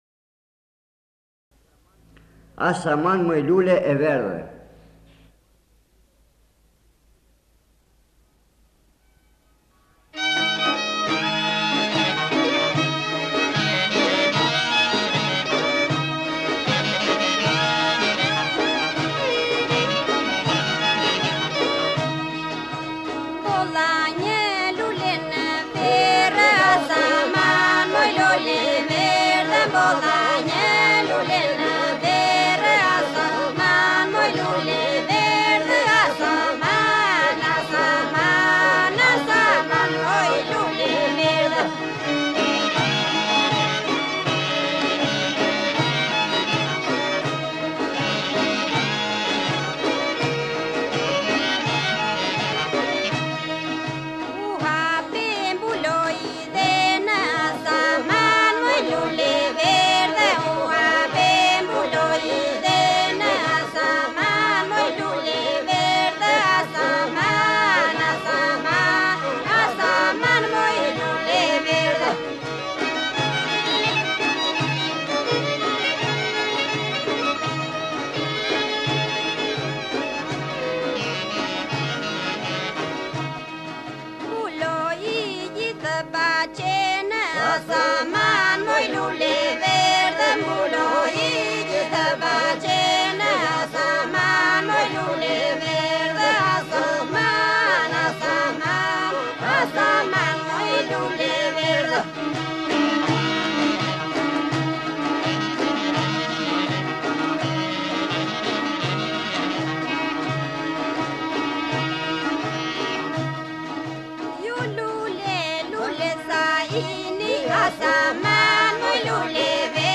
Grup popullor iso polifonik me vegla i viteve 1960-1970.
Këto saze përcollën me saze këngën tradicionale iso-polifonike labe të zonës së Labovës.